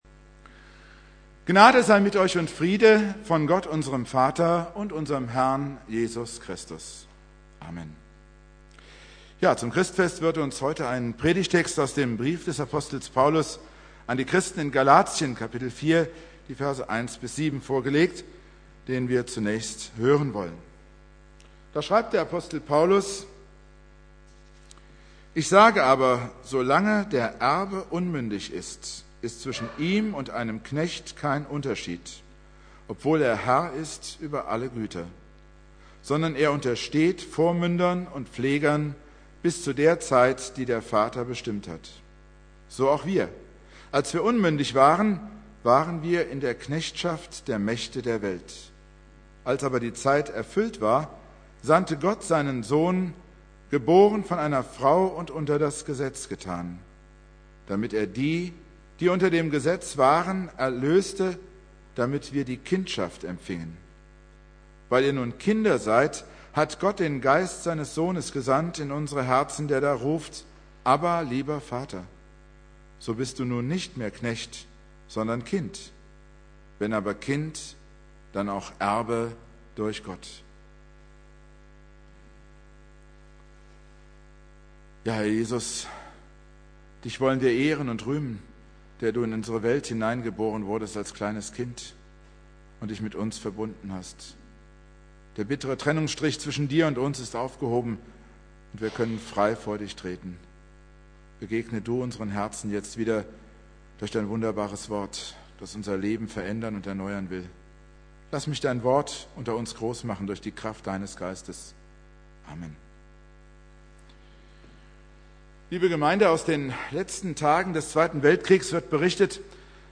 Predigt
1.Weihnachtstag